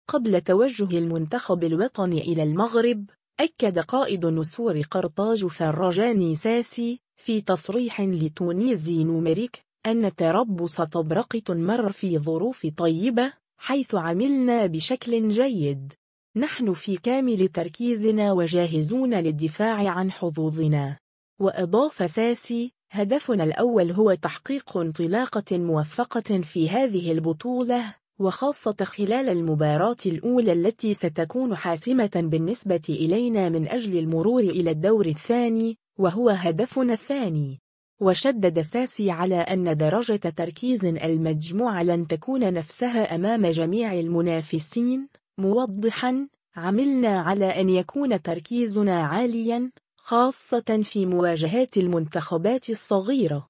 قبل توجه المنتخب الوطني إلى المغرب، أكد قائد نسور قرطاج فرجاني ساسي، في تصريح لـ«Tunisie Numérique»، أن «تربص طبرقة مرّ في ظروف طيبة، حيث عملنا بشكل جيد. نحن في كامل تركيزنا وجاهزون للدفاع عن حظوظنا».